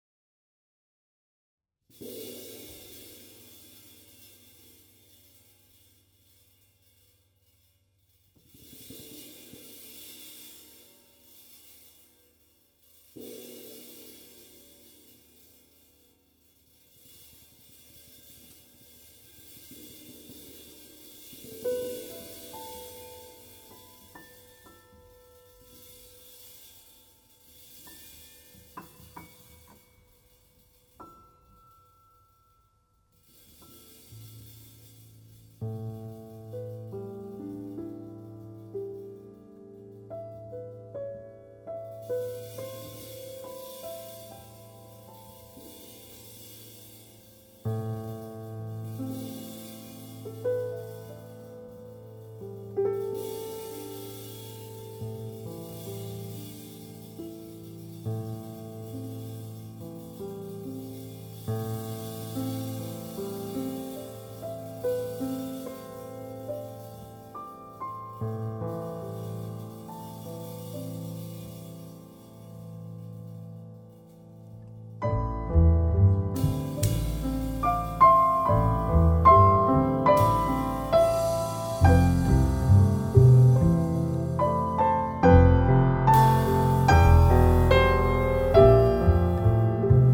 klavir
kontrabas
bobni